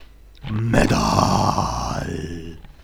I was kinda bored, so I ruined my throat doing this (I recorded it like 10 times in different styles and tones before deciding that I wasn't going to be able to get it right).
My particular vision of how metal should be:
Metal2.wav